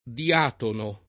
diatono